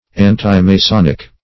antimasonic - definition of antimasonic - synonyms, pronunciation, spelling from Free Dictionary